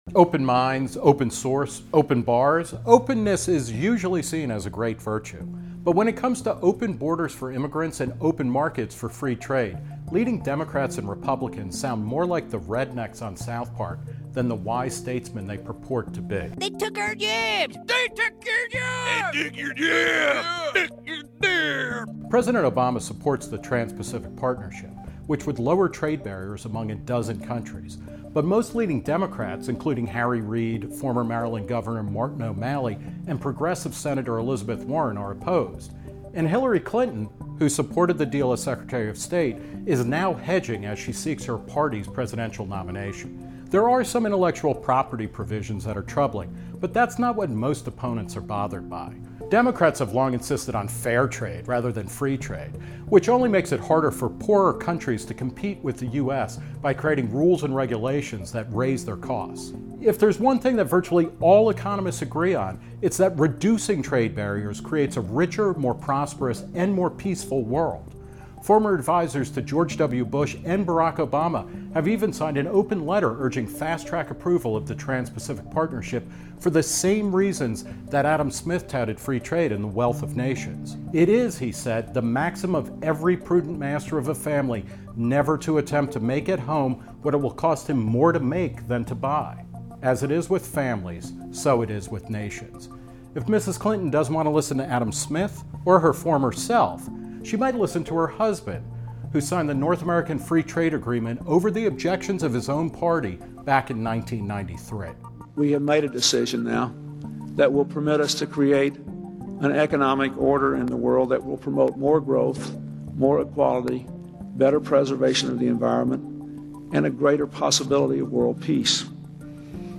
Written and narrated by Nick Gillespie.